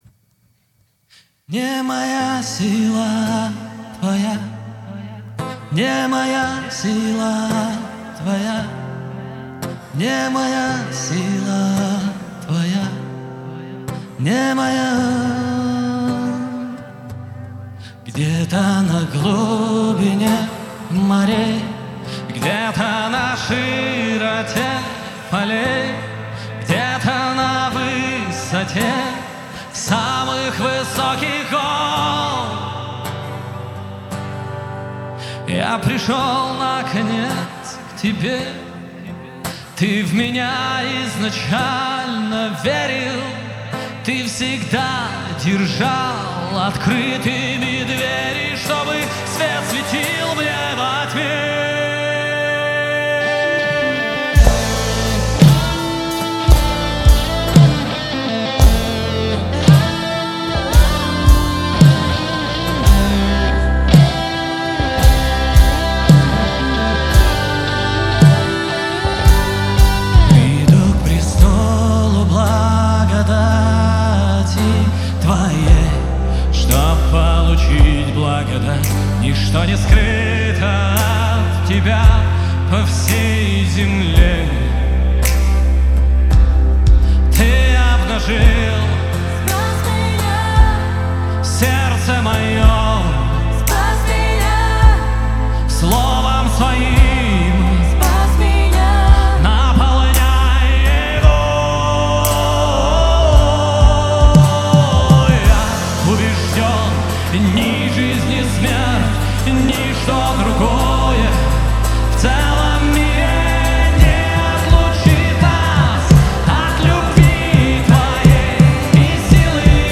53 просмотра 255 прослушиваний 7 скачиваний BPM: 169